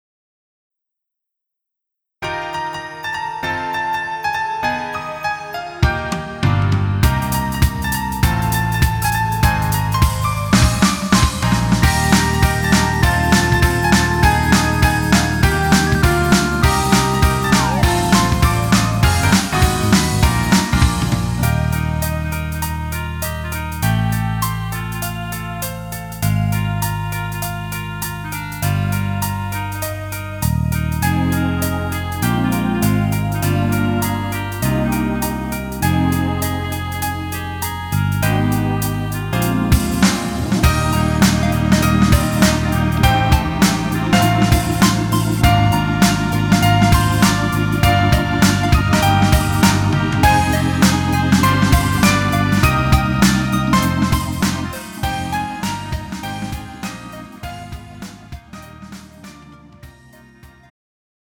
음정 -1키 3:50
장르 가요 구분 Pro MR